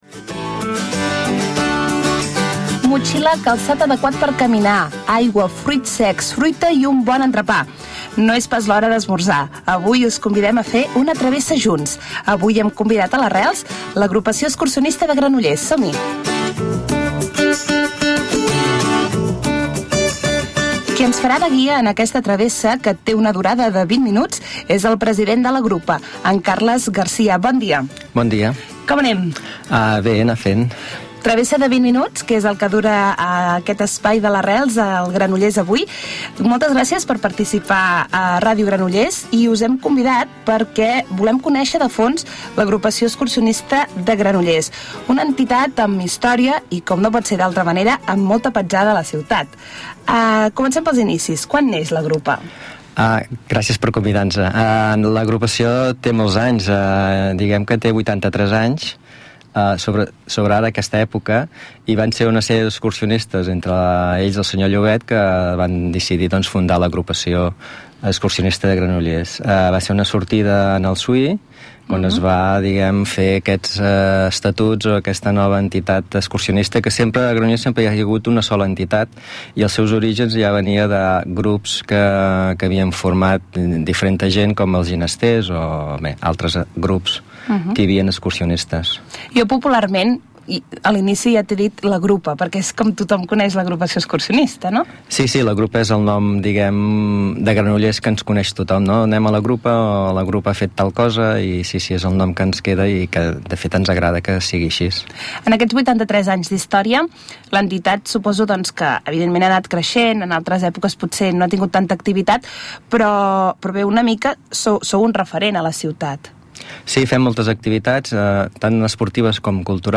Entrevista a Ràdio Granollers - Agrupació Excursionista de Granollers